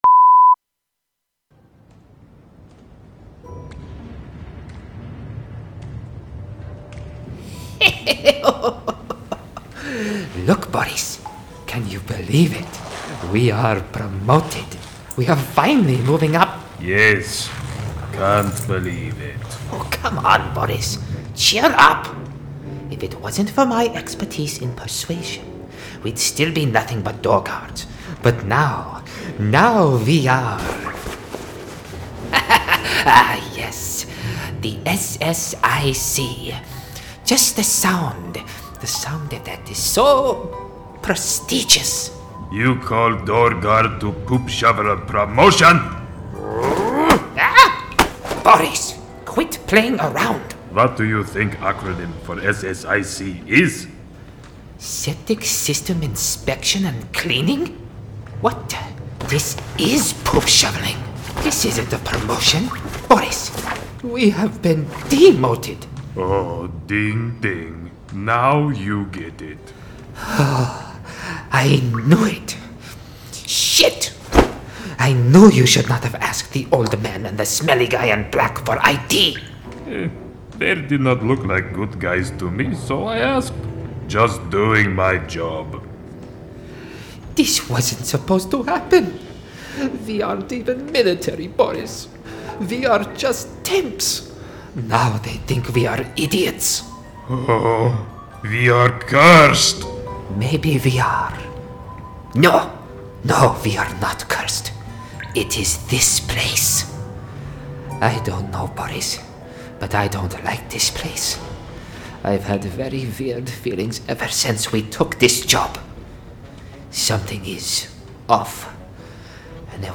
Character Voice Demos